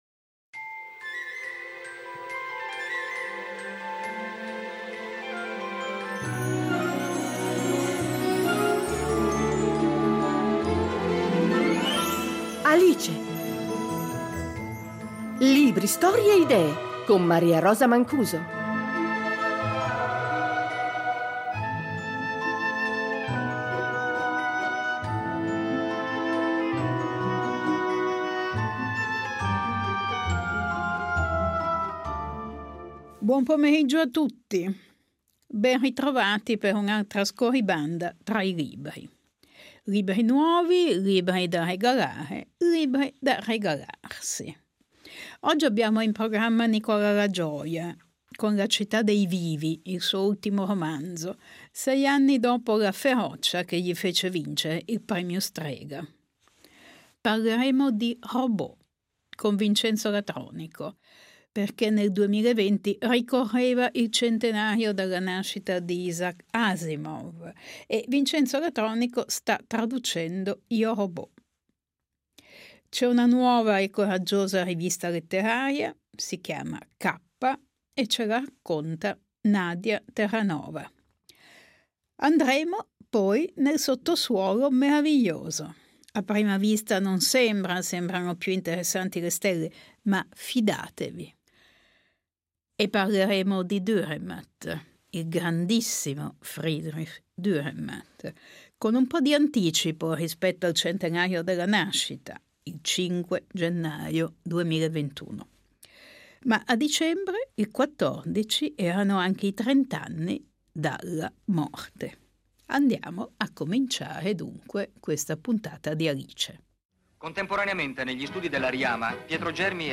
Negli altri spazi del programma, un’intervista a Nicola LaGioia : il direttore del Salone del Libro di Torino ha appena pubblicato “La città dei vivi” . Parleremo di quel che sta sotto i nostri piedi, a volte più interessante del cielo stellato.